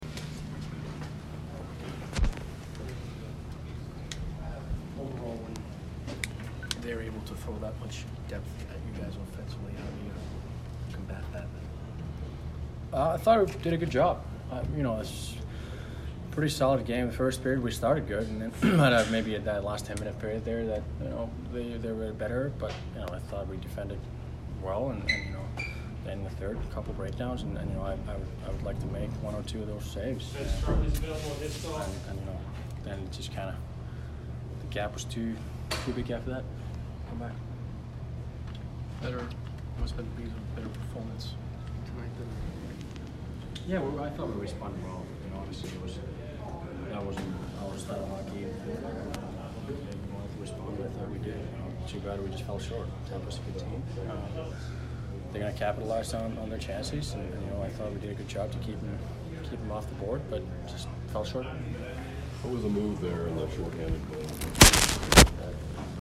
Tuukka Rask post-game 12/06